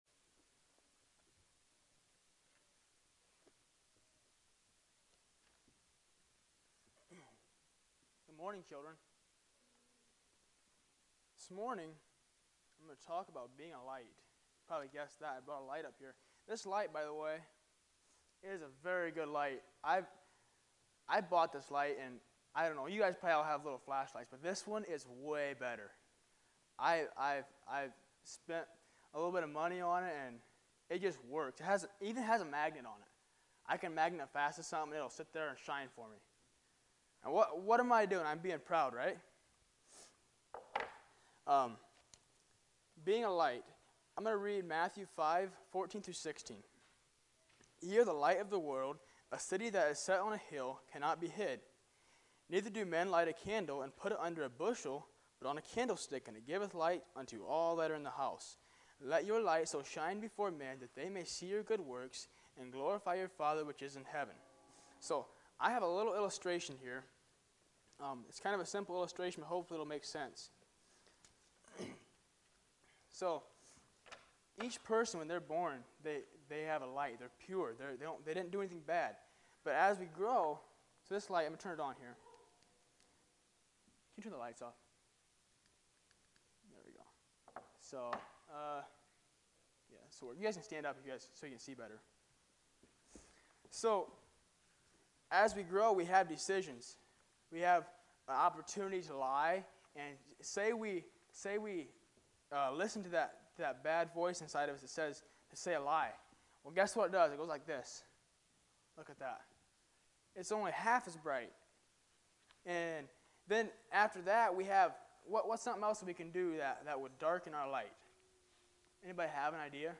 Children's Lessons